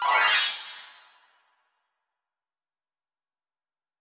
sound-transitions.mp3